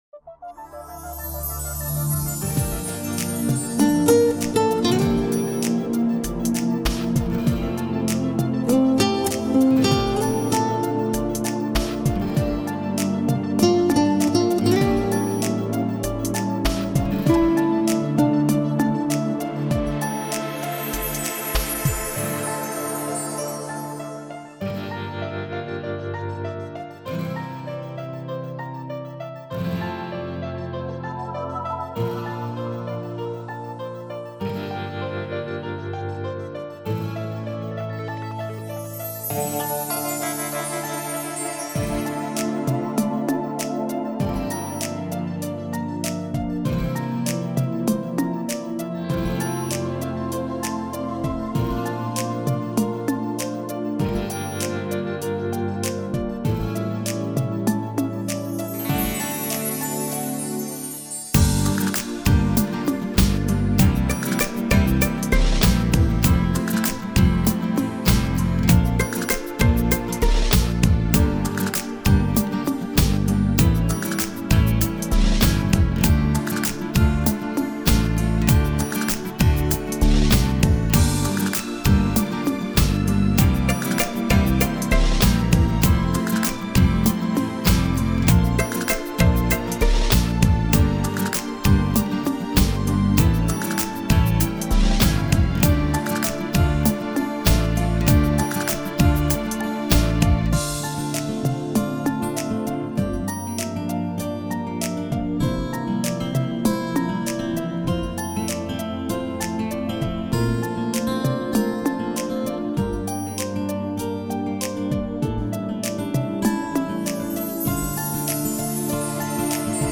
минусовка версия 122489